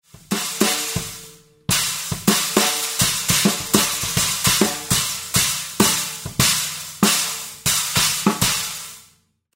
12" FX SPIRAL STACKER
「スタッカー」の名の通り、他のシンバルに重ねて使用することで、非常にユニークなトラッシーサウンドと、ドラマーの個性を演出。
12_SpiralStackerOver14Trashformer_Example.mp3